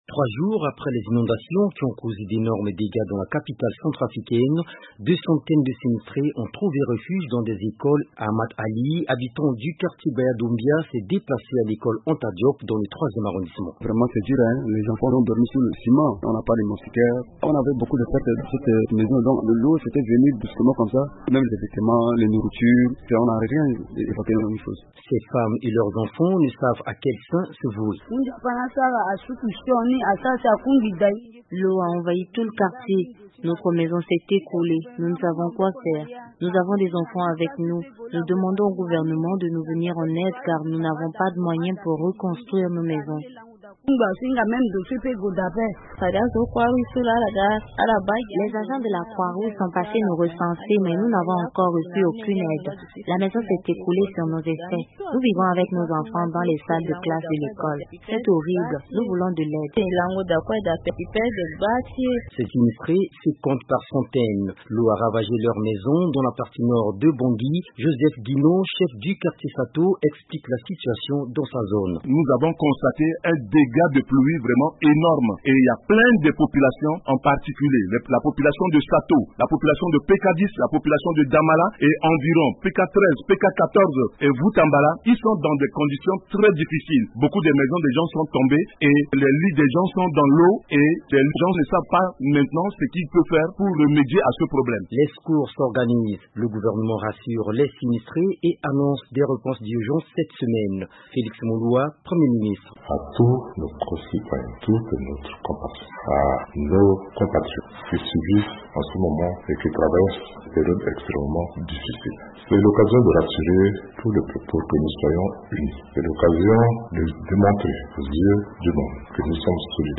depuis la capitale centrafricaine.